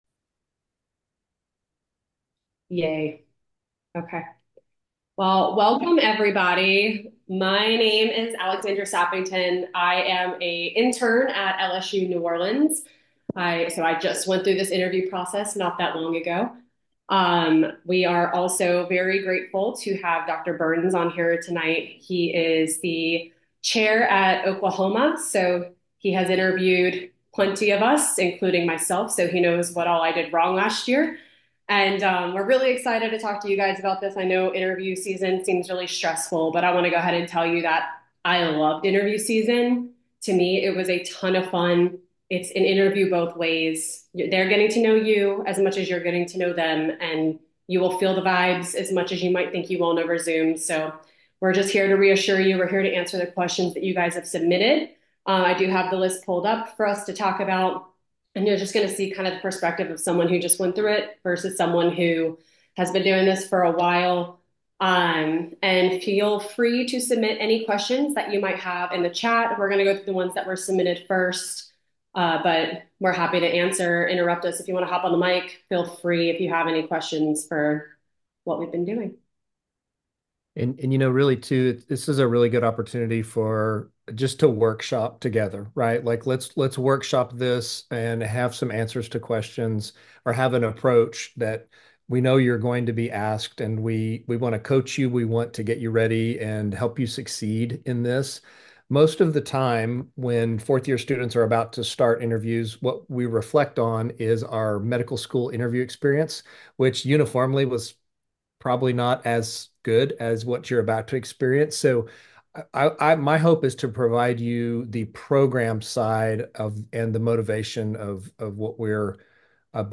Virtual Lectures